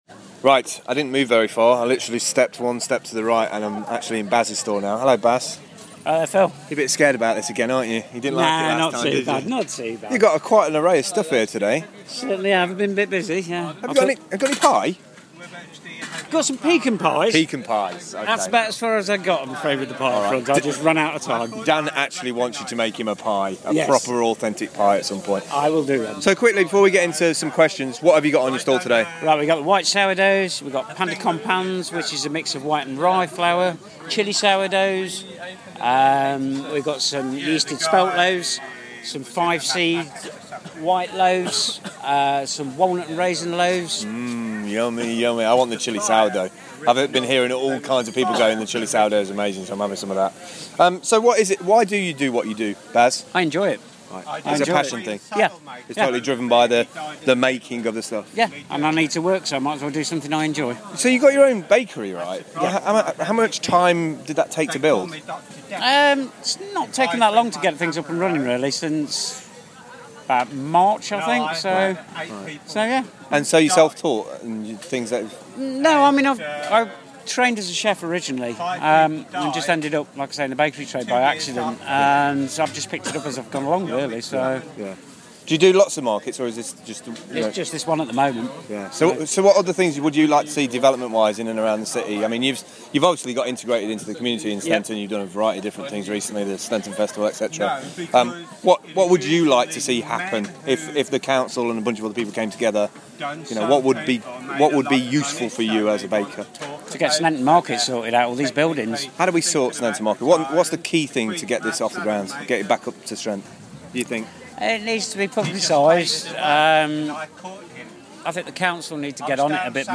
sneinton market